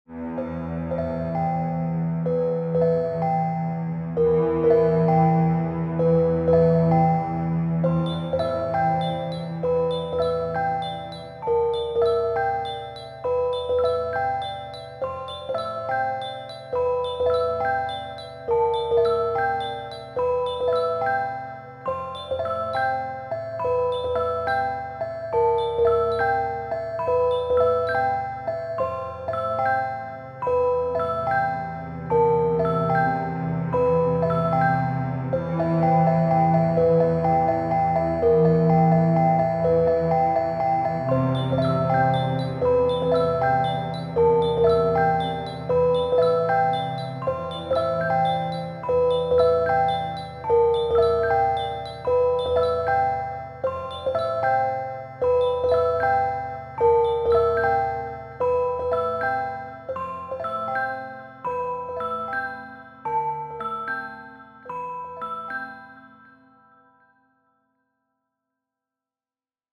Die Musik sollte eine fantastische und mysteriöse Stimmung haben.
Somit hatten wir ein digitales Orchester schnell und einfach zur Hand.
Zum Ausprobieren wurden ein paar musikalische Konzeptionen erstellt.